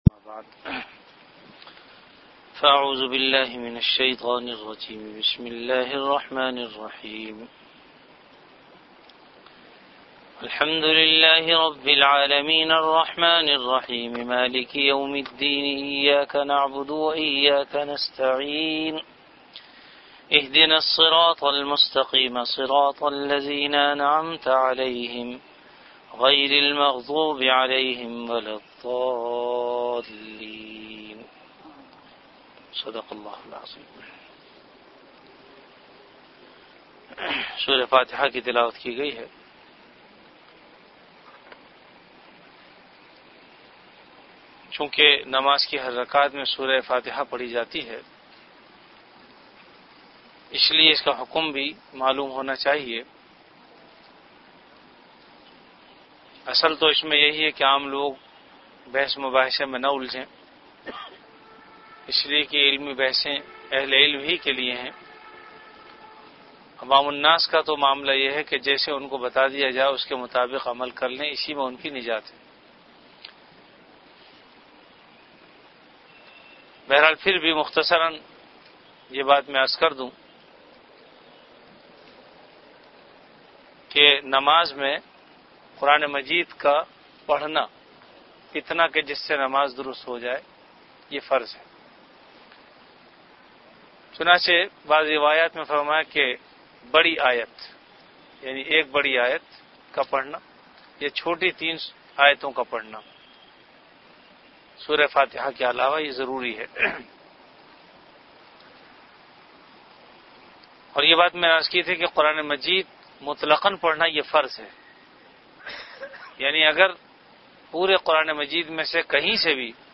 Dars-e-quran · Jamia Masjid Bait-ul-Mukkaram, Karachi